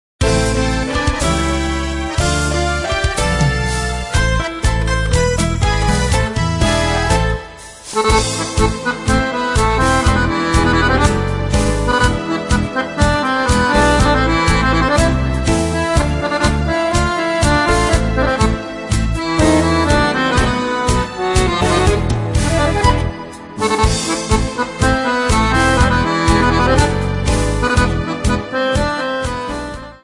Accordion Music 3 CD Set.